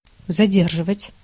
Ääntäminen
US : IPA : [ˈɹi.ˈtɑɹd]